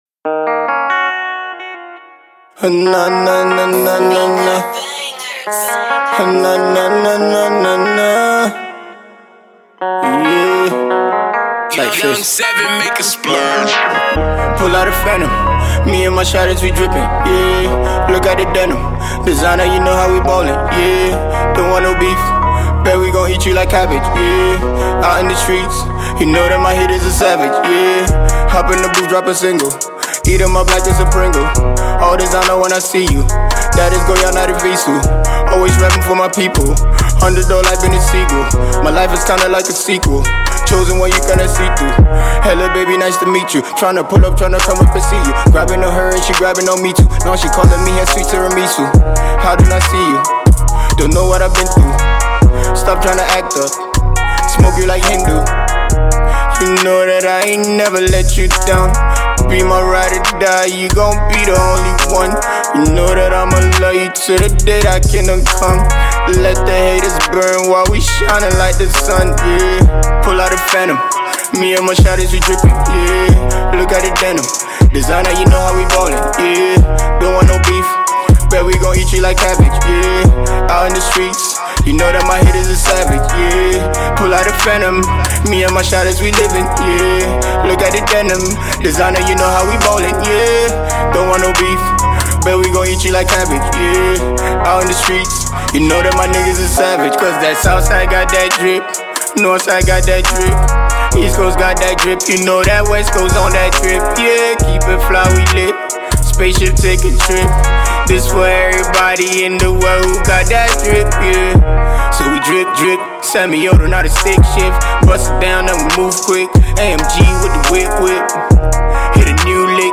wild jam
The France-based Ghanaian Rapper/Singjay